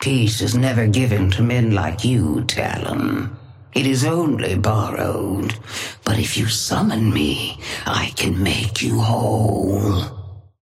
Sapphire Flame voice line - Peace is never given to men like you, Talon.
Patron_female_ally_orion_start_04.mp3